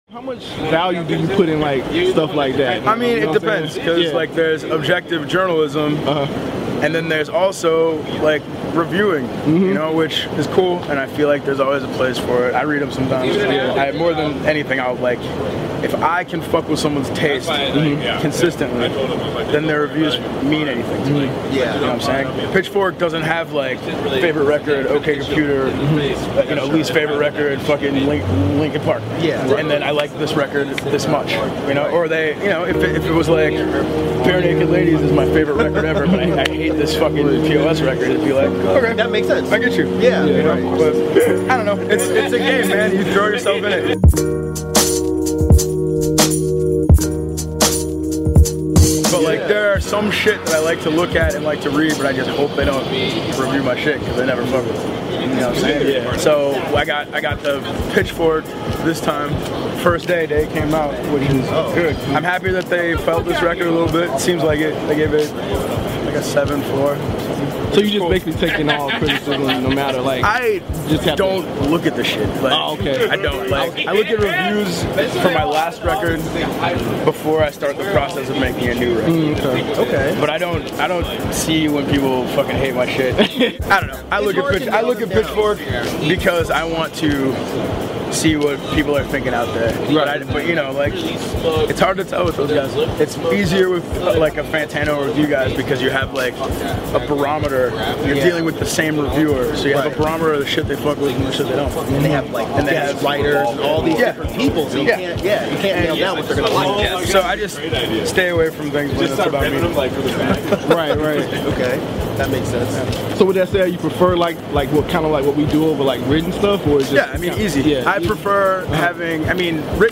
P.O.S of Doomtree Interview | DEHH